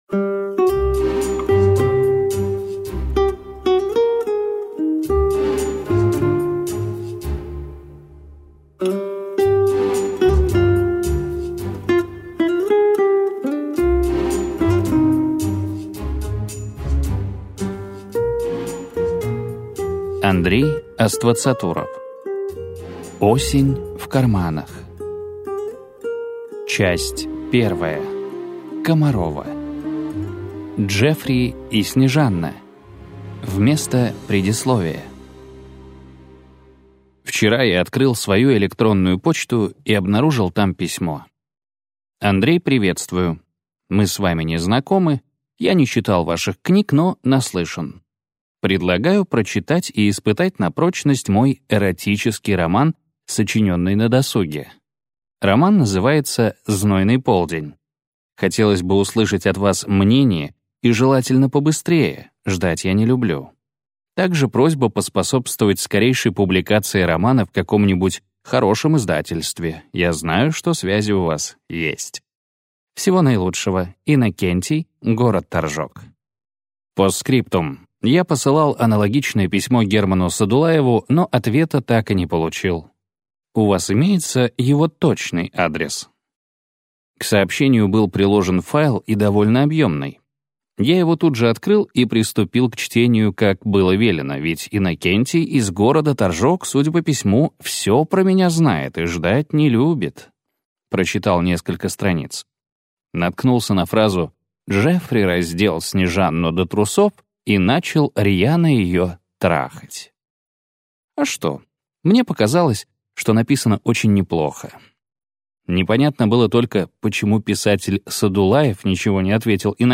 Аудиокнига Осень в карманах - купить, скачать и слушать онлайн | КнигоПоиск